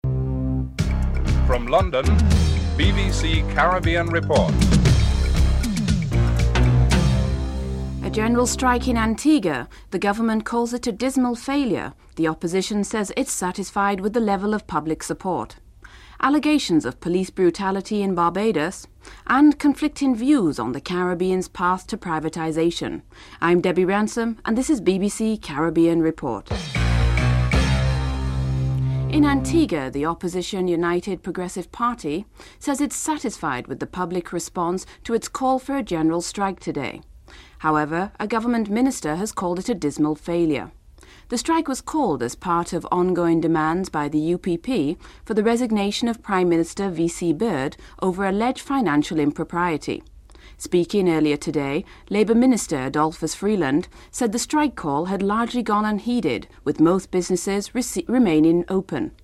The report on the last segment ends abruptly.